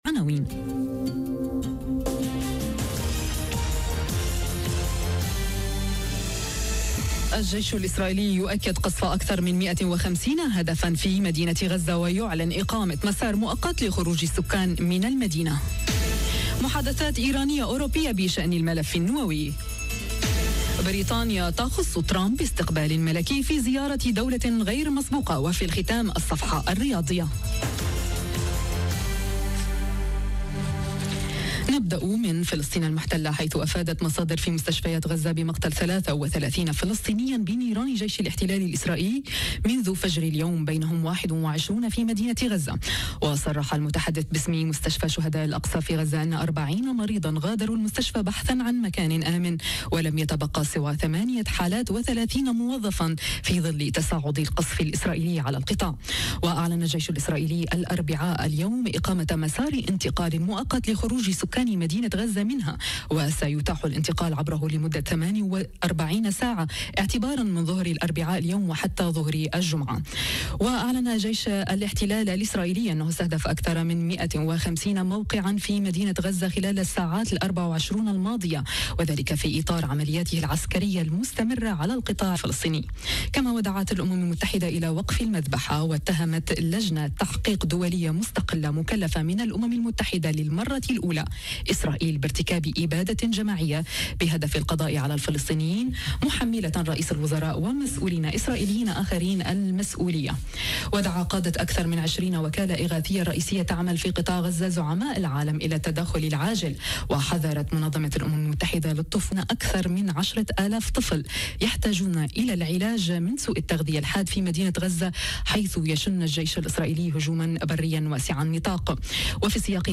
نشرة أخبار الظهيرة: الجيش الإسرائيلي يؤكد قصف "أكثر من 150 هدفا" في مدينة غزة - Radio ORIENT، إذاعة الشرق من باريس